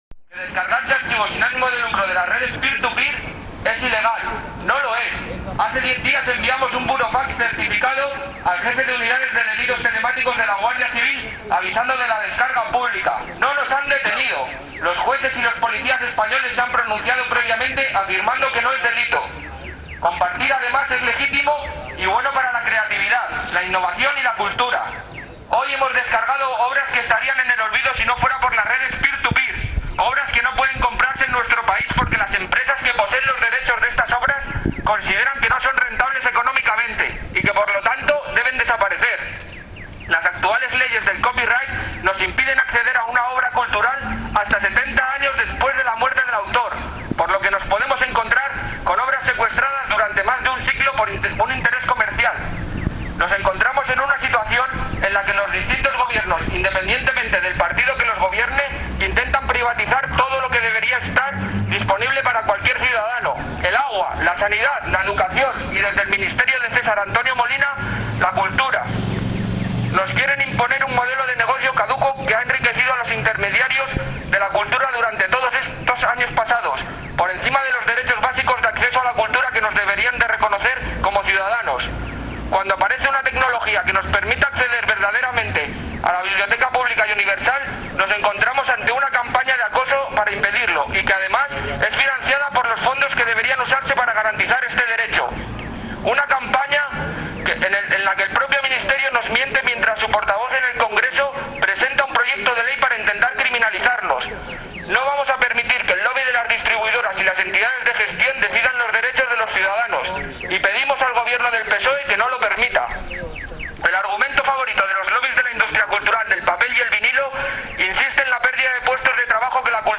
Manifestación ante la sede del PSOE
El comunicado de los manifestantes…